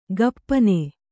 ♪ gappane